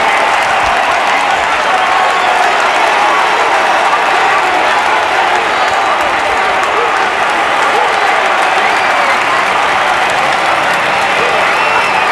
rr3-assets/files/.depot/audio/sfx/ambience/ambience_crowd.wav
ambience_crowd.wav